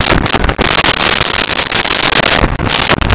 Glass On Bar With Slide 01
Glass On Bar With Slide 01.wav